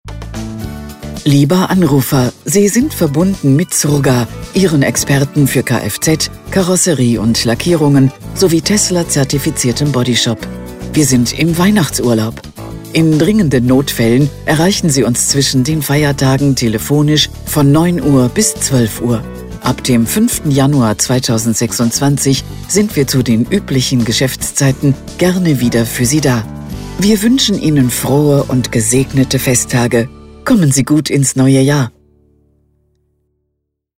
Telefonansagen mit echten Stimmen – keine KI !!!
Weihnachtsansage
Zurga-Weihnachten.mp3